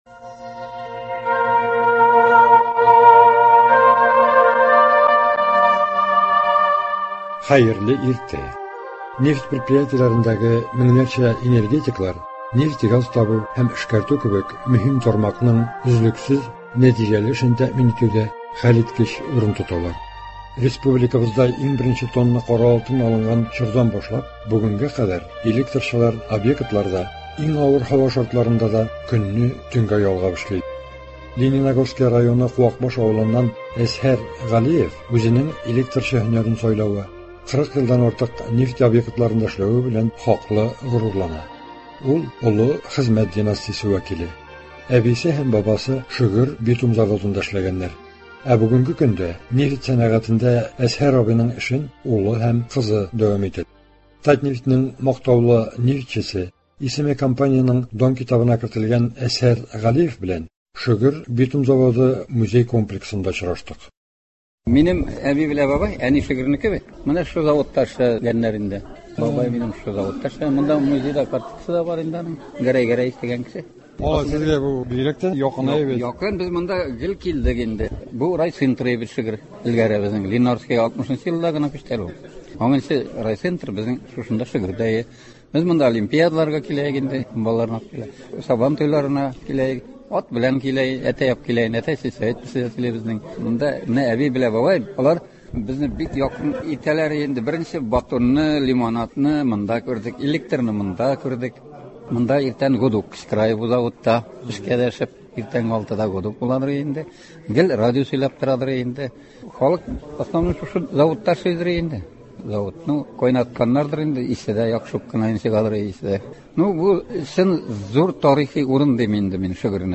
репортажларда кара алтын табучыларның хезмәт һәм ял шартлары, мәдәният һәм социаль өлкәдәге яңалыклар чагылыш таба.